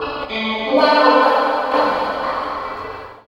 64 GUIT 5 -L.wav